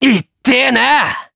Neraxis-Vox_Damage_jp_03.wav